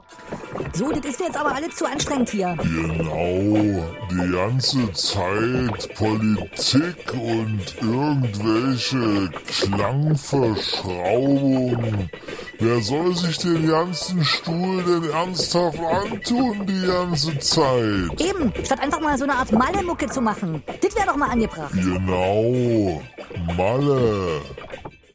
So - das ganze Wochenende "Glitchie und Glutchie"-Breaks für mein neues Album produziert.